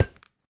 Minecraft Version Minecraft Version latest Latest Release | Latest Snapshot latest / assets / minecraft / sounds / block / bone_block / step5.ogg Compare With Compare With Latest Release | Latest Snapshot